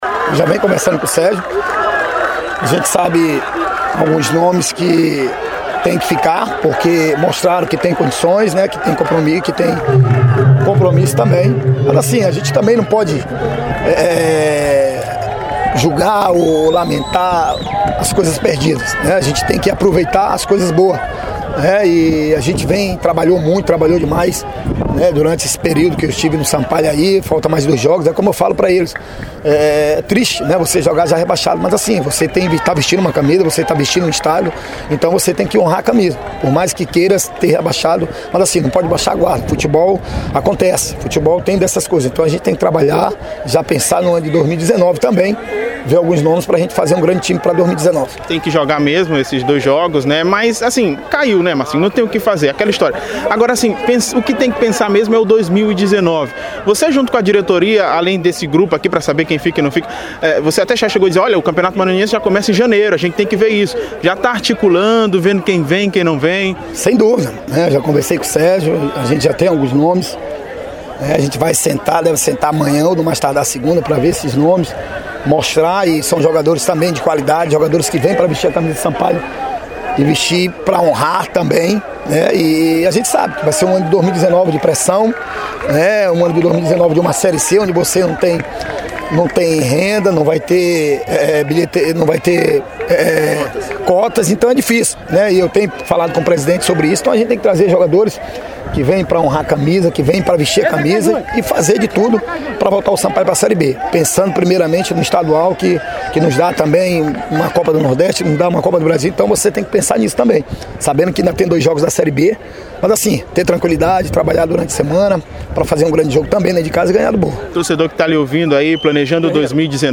A entrevista